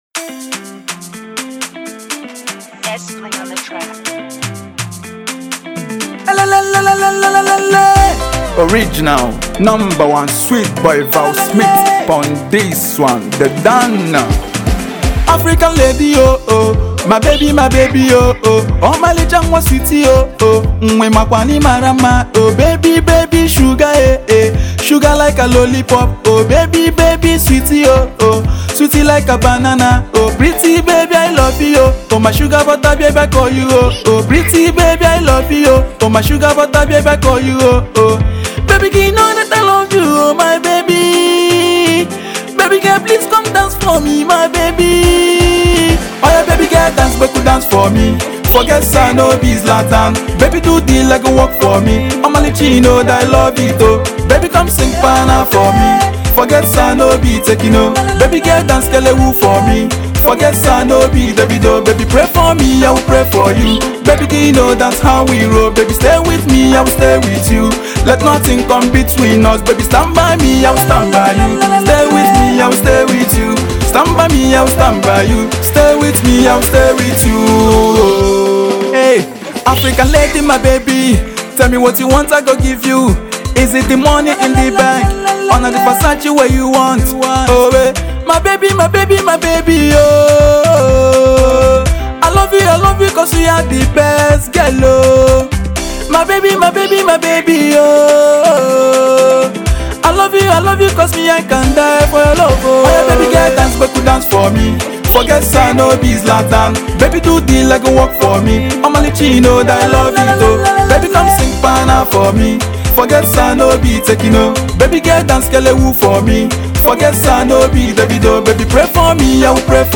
off to your feet melodious jam